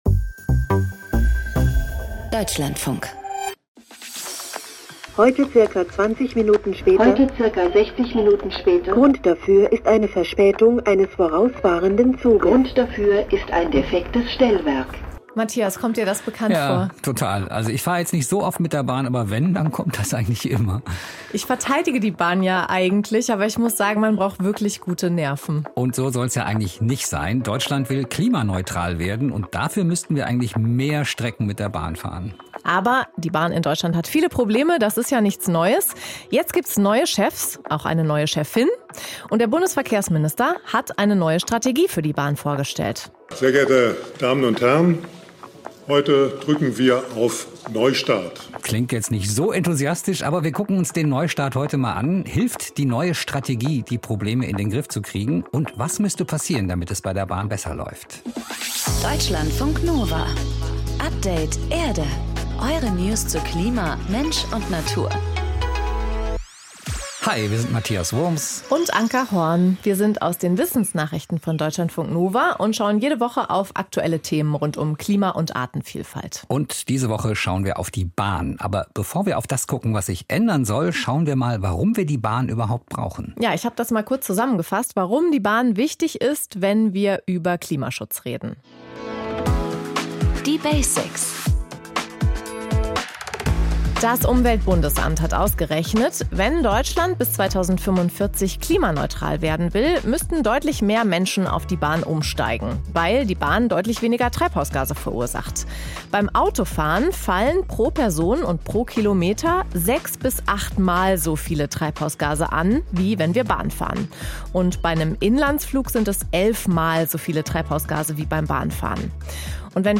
15:28 - Deine Minute Natur: Wasserfall in den Allgäuer Alpen